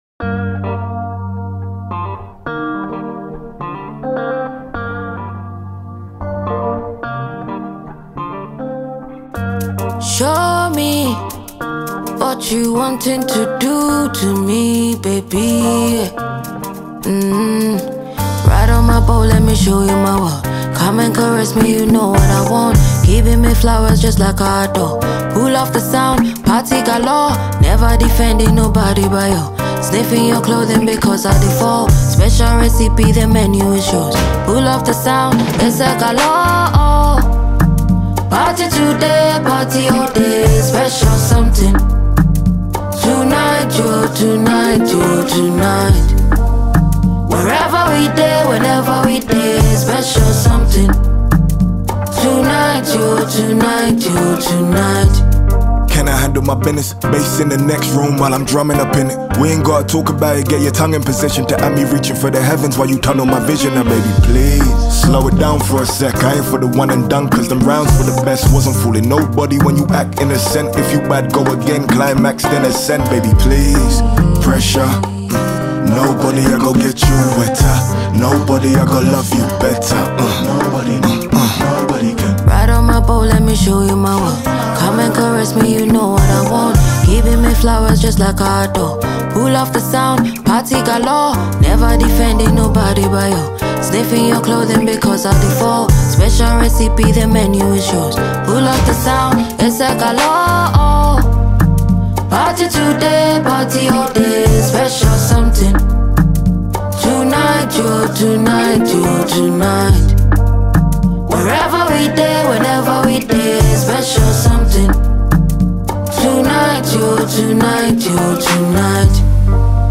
Ghanaian singer and songwriter